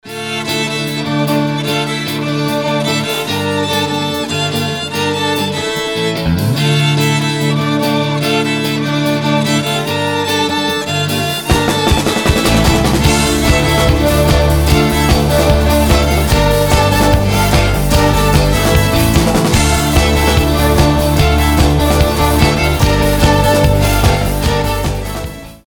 Рингтоны без слов
Панк-рок , Скрипка , Фолк-рок , Symphonic rock